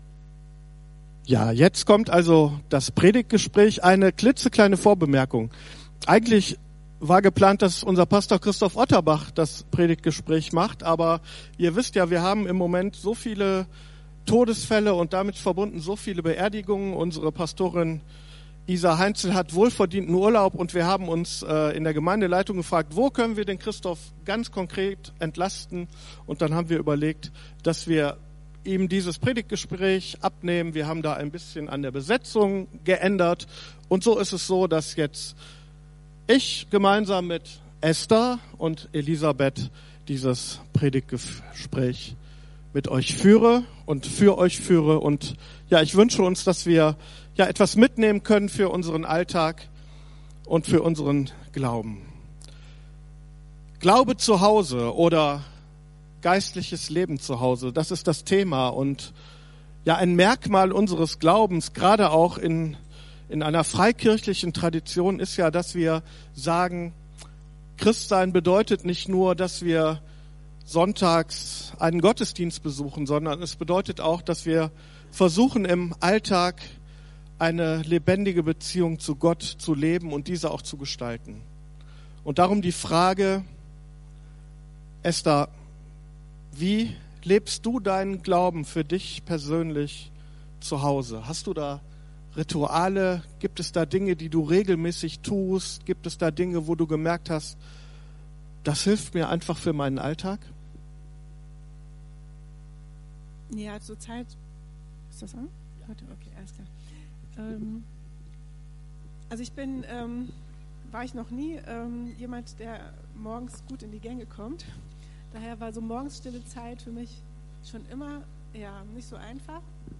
Geistliches Leben zu Hause / 3er Gesprächsteam aus der Gemeinde ~ Predigt Podcast FeG Wuppertal Vohwinkel Podcast